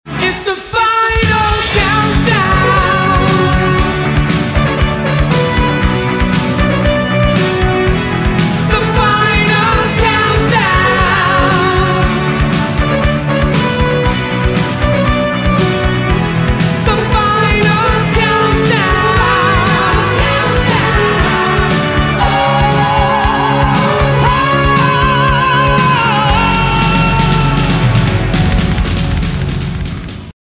жанр, категория рингтона ЖАНР: РИНГТОНЫ 80е-90е